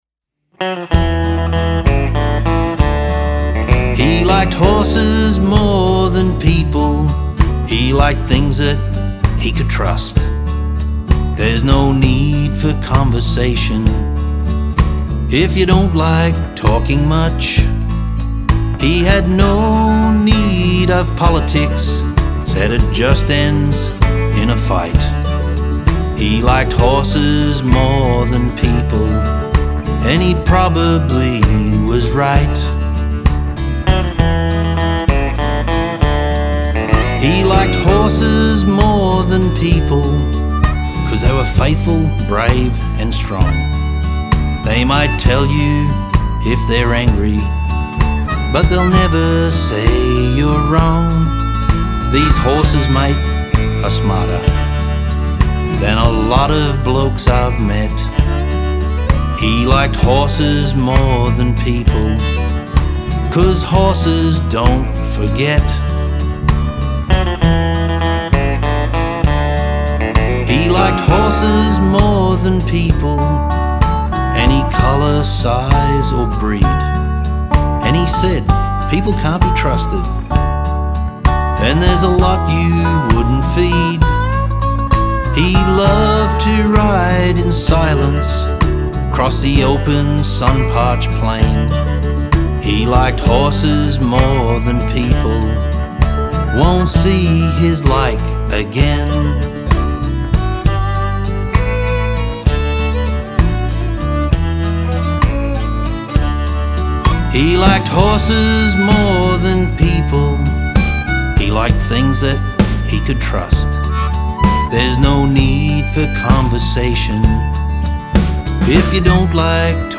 Australian bush ballads
classic country music